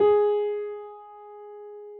piano_056.wav